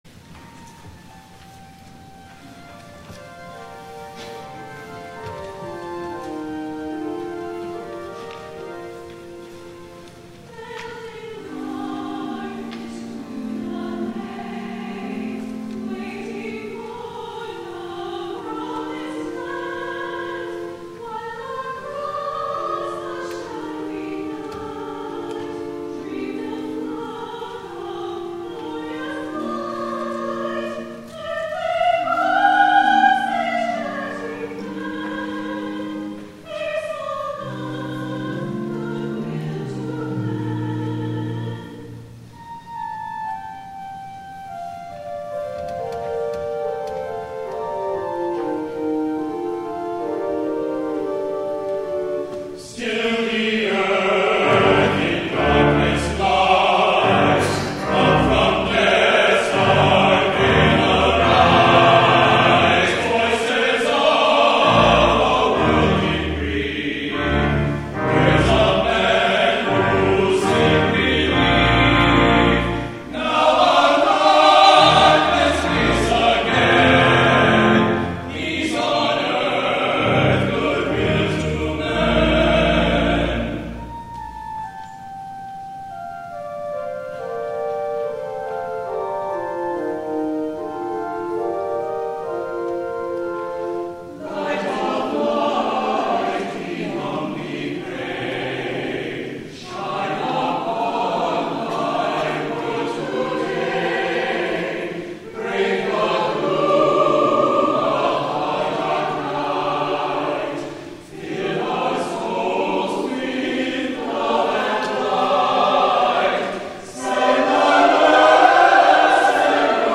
FIRST SUNDAY OF ADVENT
THE ANTHEM